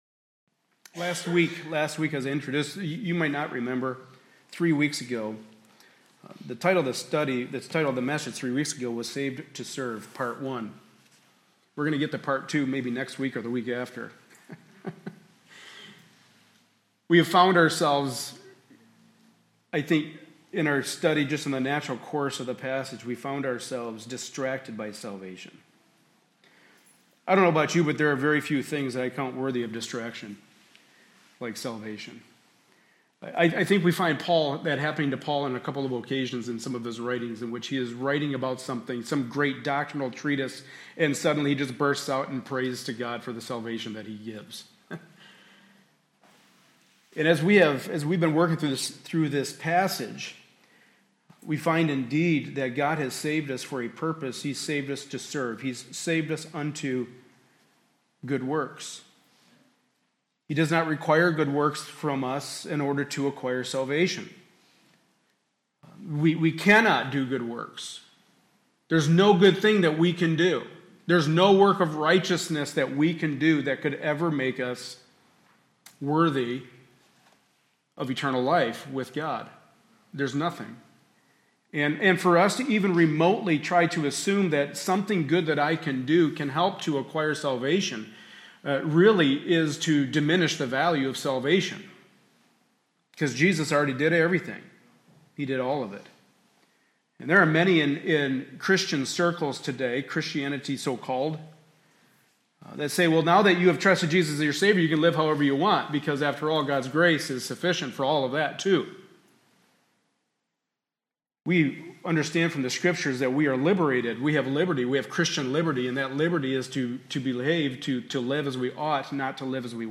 Passage: Titus 3:1-8 Service Type: Sunday Morning Service